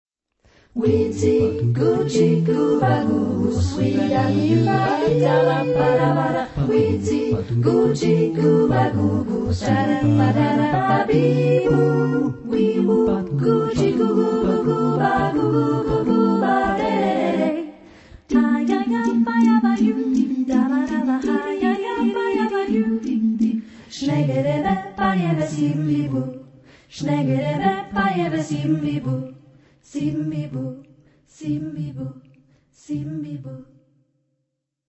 Texte en : onomatopées
Caractère de la pièce : jazzy ; joyeux ; rythmé
Type de choeur : SATB  (4 voix mixtes )
Tonalité : accords de jazz